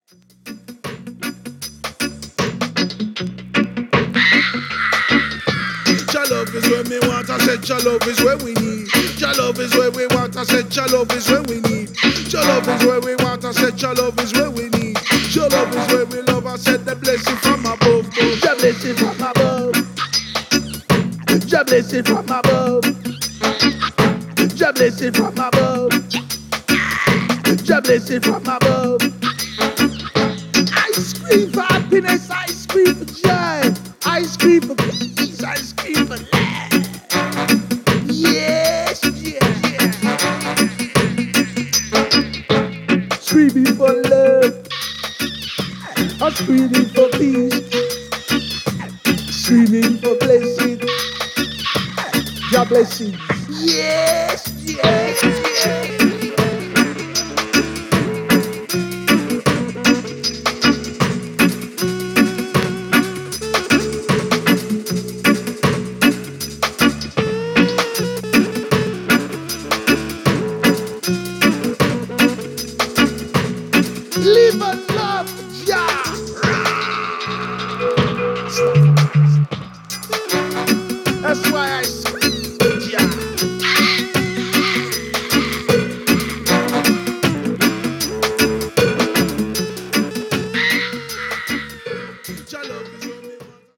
UK New Roots